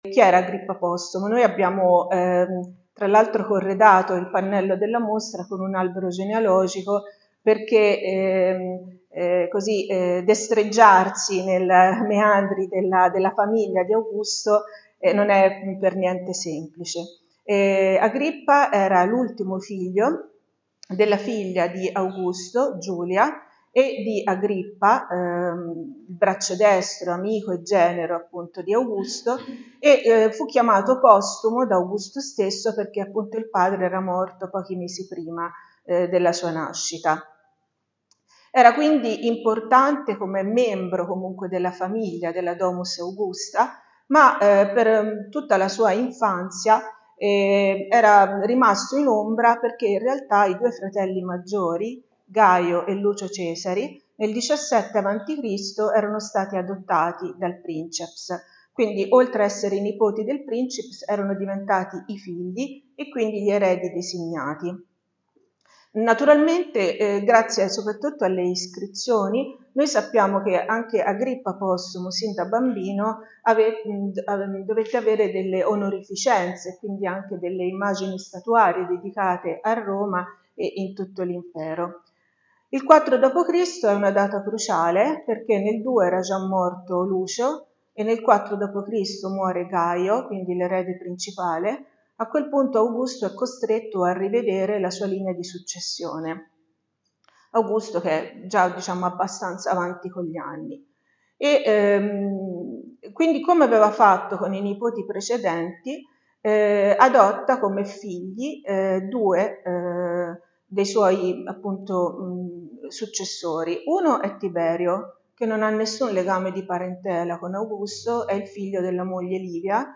Il VIDEO dell'inaugurazione della mostra su Agrippa Postumo ai Capitolini - Radio Colonna